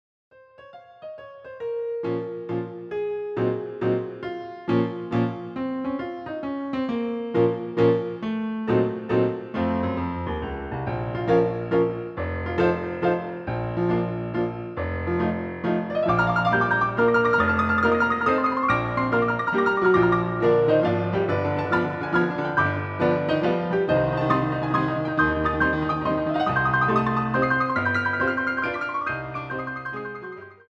using the stereo sampled sound of a Yamaha Grand Piano